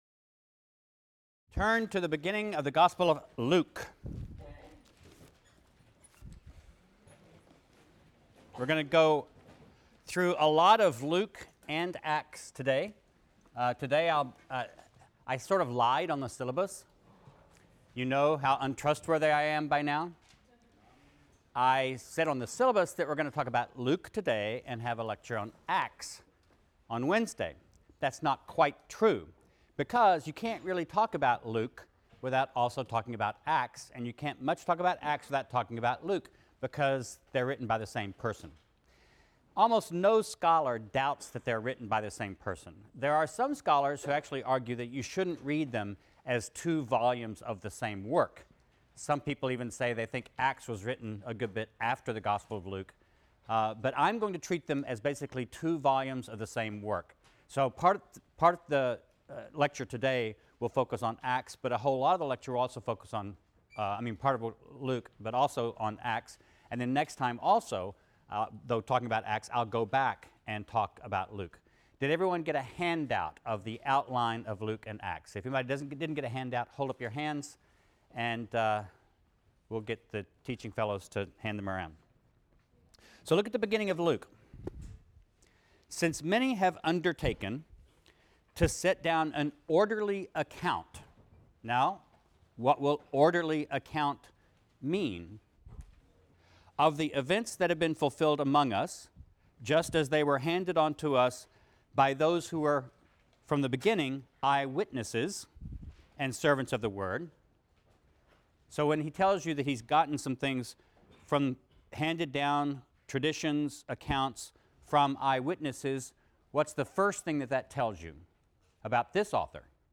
RLST 152 - Lecture 9 - The Gospel of Luke | Open Yale Courses